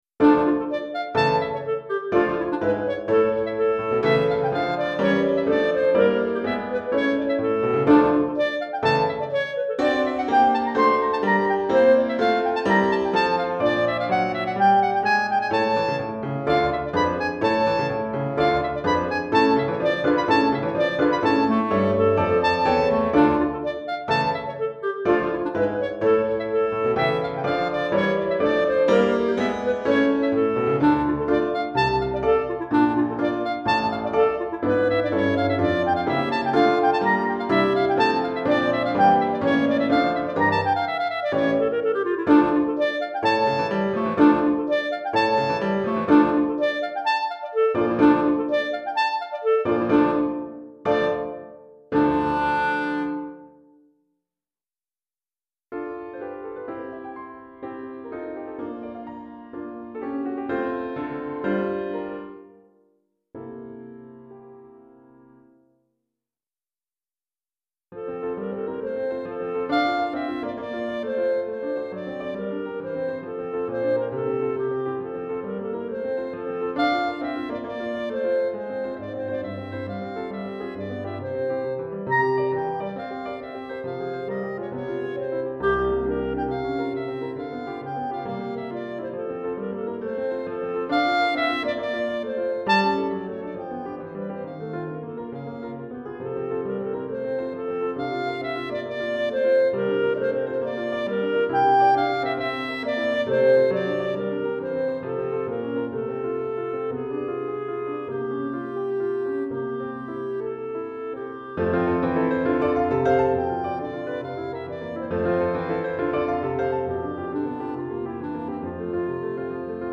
Clarinette et Piano